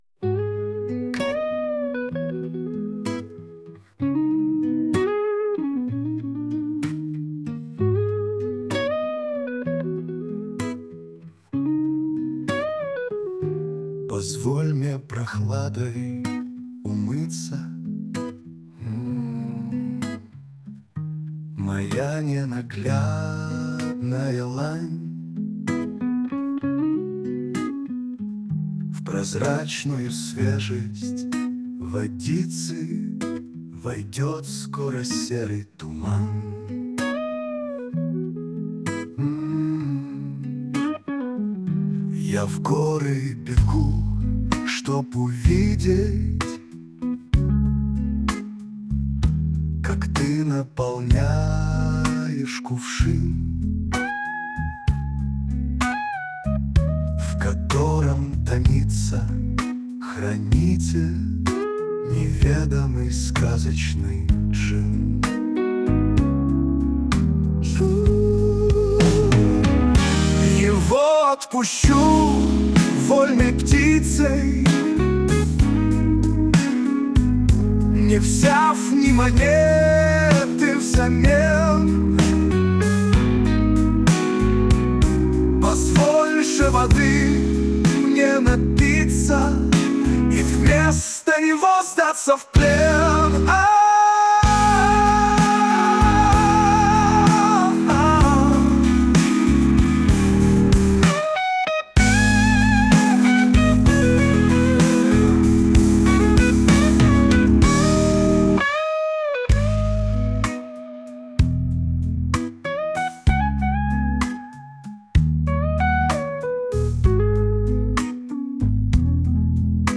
Блюз (1232)